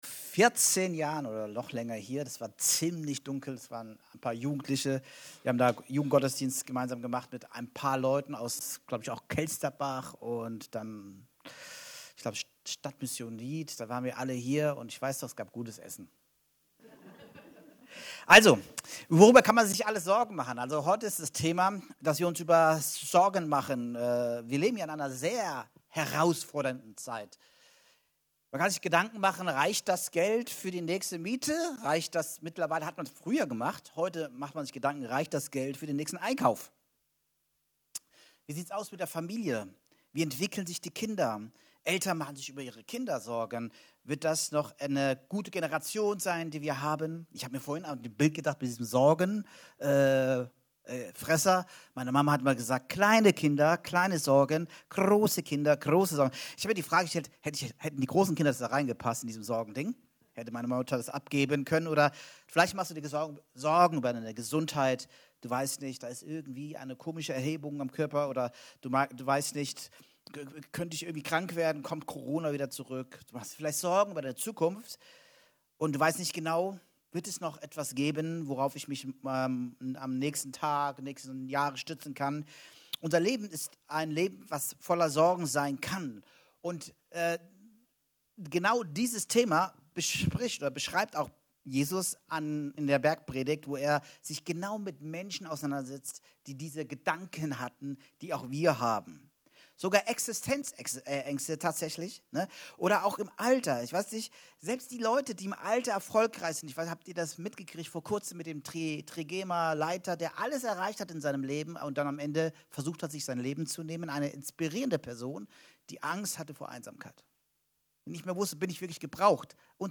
Evangelisch-Freikirchliche Gemeinde Kelkheim - Predigten anhören